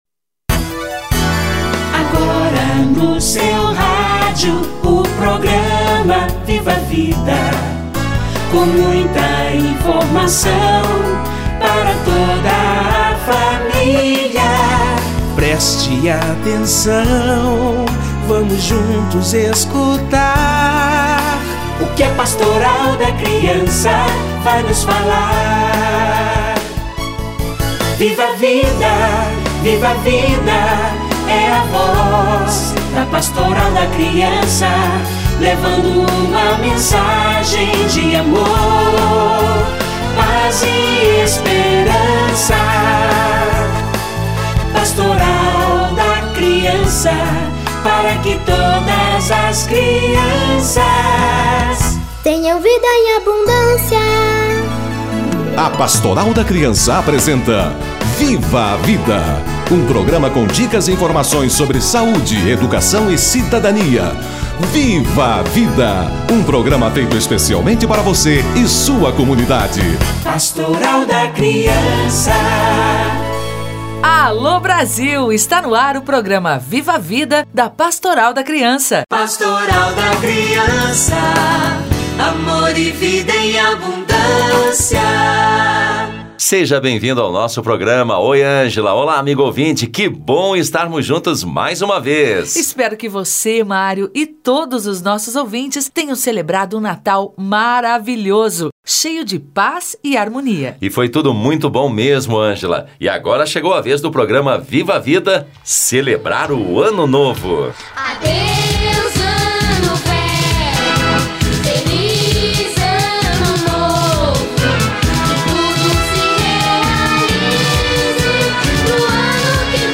Ano Novo - Entrevista